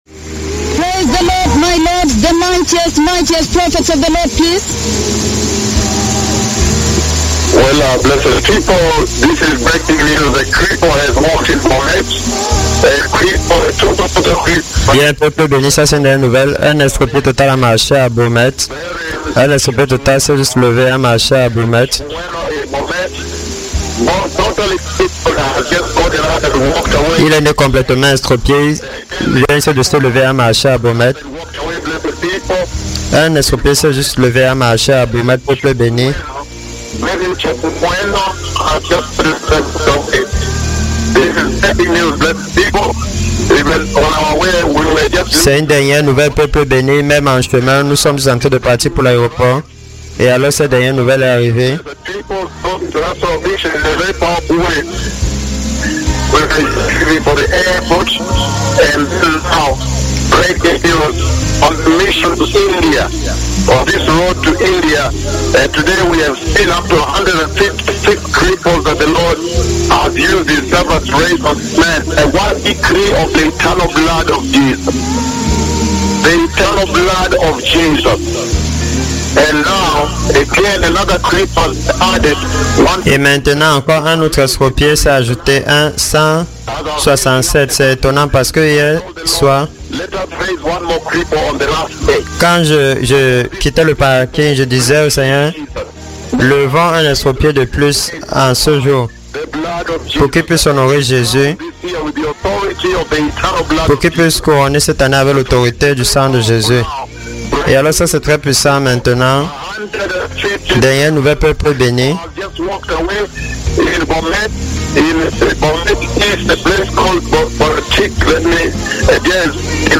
PRÉSENTÉE PAR LES DEUX MÉGA PUISSANTS PROPHÈTES DE JÉHOVAH.
Diffusion capturée depuis JESUS IS LORD RADIO